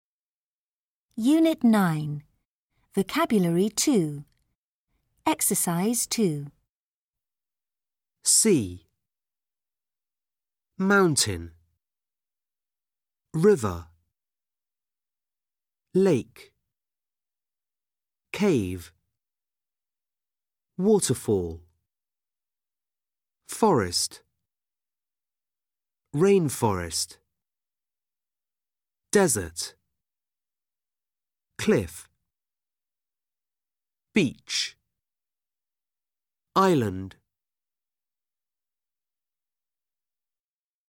Landscape features |ˈlæn(d)skeɪp| |ˈfiːtʃəz| — особенности ландшафта
Выучите эти слова наизусть, используя аудиозапись научитесь правильно их произносить.